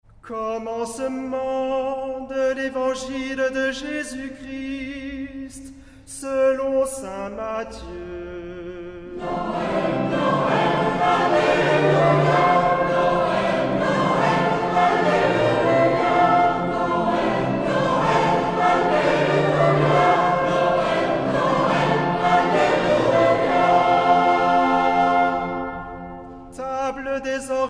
chorale